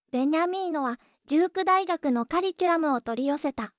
voicevox-voice-corpus